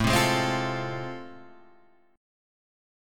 A Minor Major 7th Sharp 5th